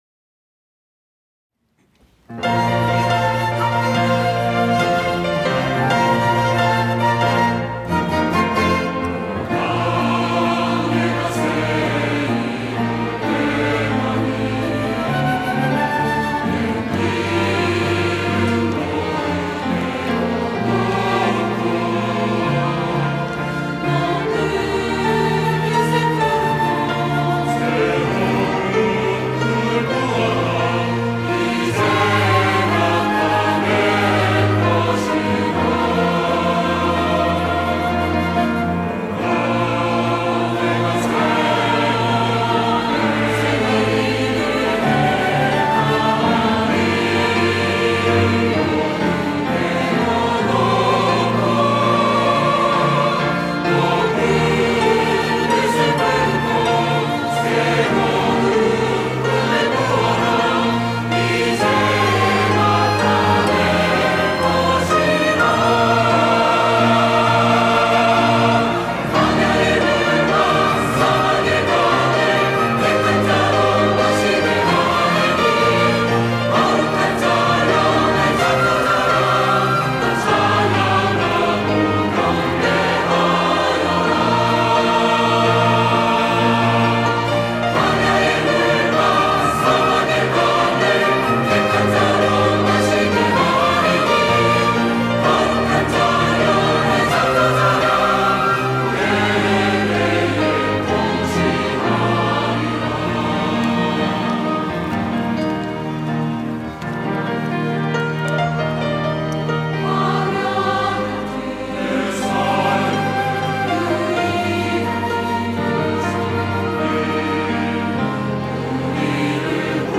호산나(주일3부) - 주를 보라
찬양대